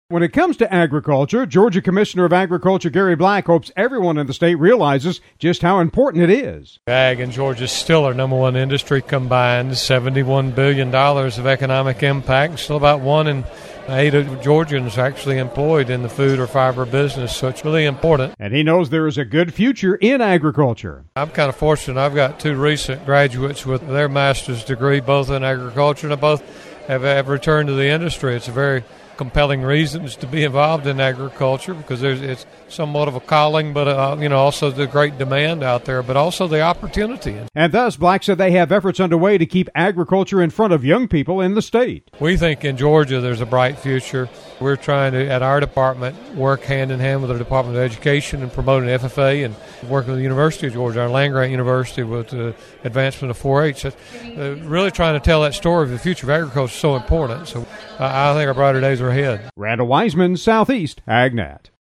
Gary Black, Georgia’s Commissioner of Agriculture, talks about the importance of agriculture in his state and what they are doing to keep youth ag programs going to support it in the future.